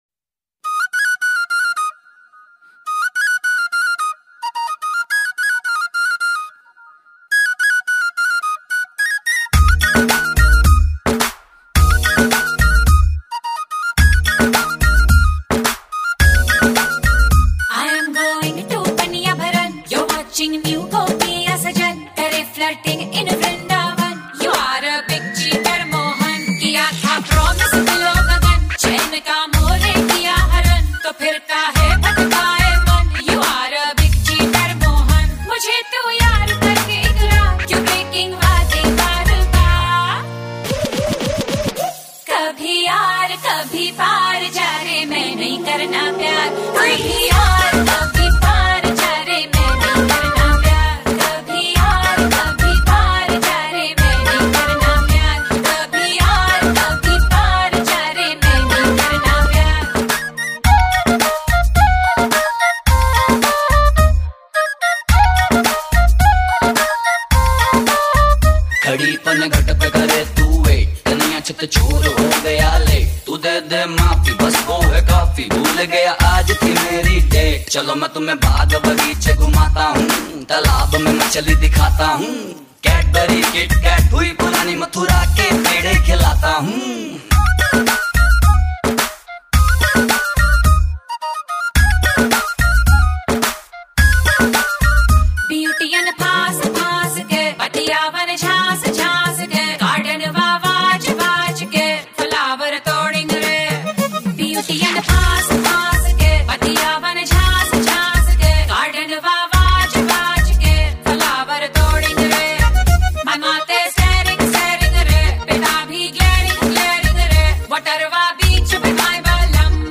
Single Indian Pop